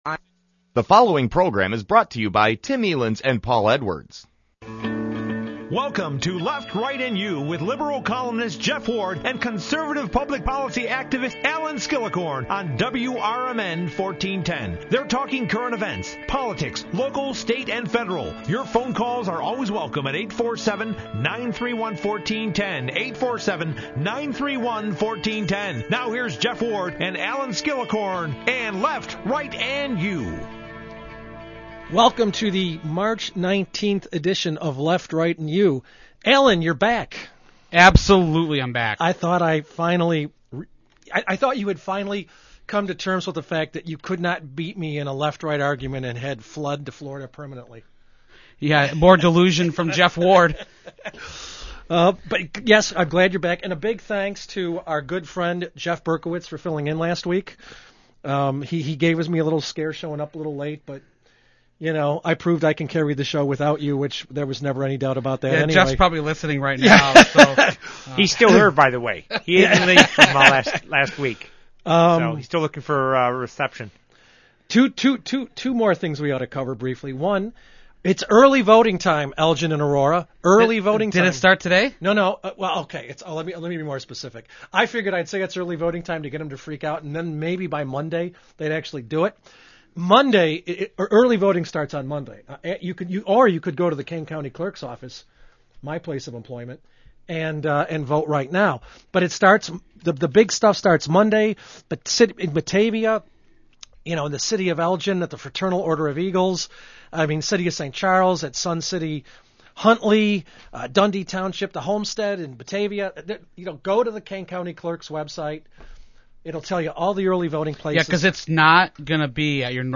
That’s right! We’ll be on the radio twice this week.